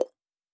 Click (20).wav